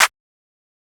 Claps
TS Clap_7.wav